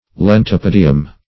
Leontopodium - definition of Leontopodium - synonyms, pronunciation, spelling from Free Dictionary
leontopodium.mp3